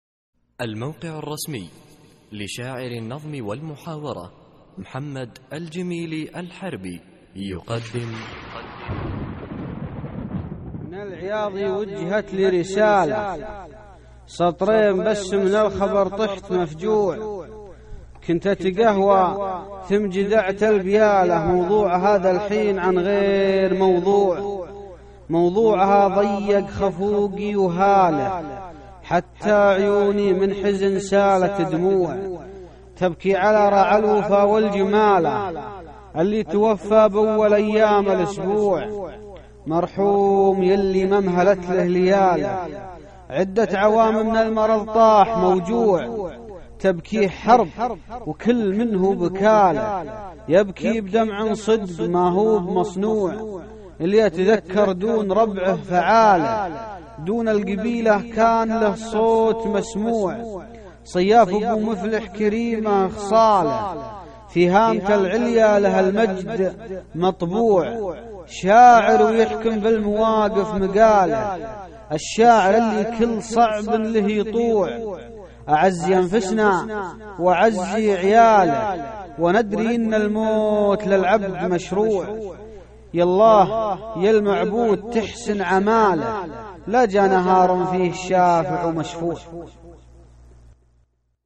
القصـائــد الصوتية